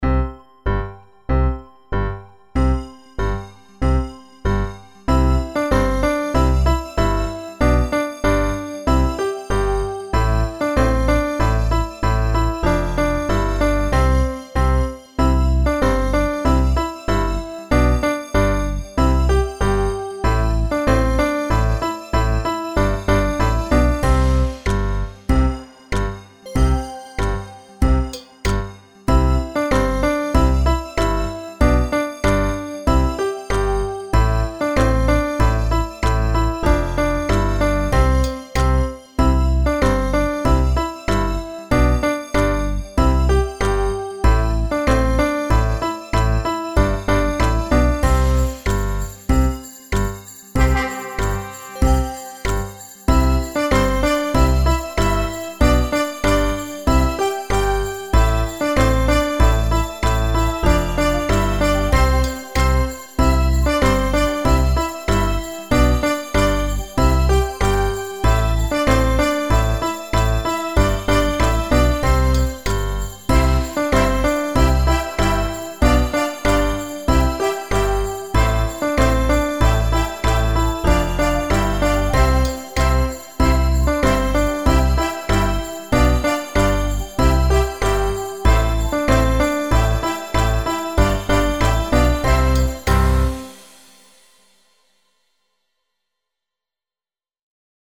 Children’s song, U.S.A.
Karaoke Songs for Kids
instrumental, 1:38 – 4/4 – 95 bpm